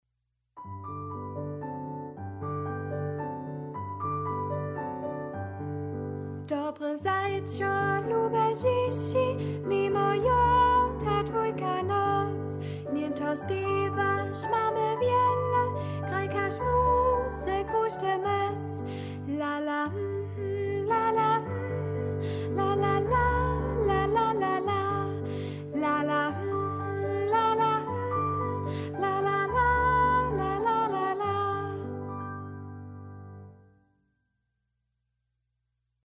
melodija: serbski ludowy spiw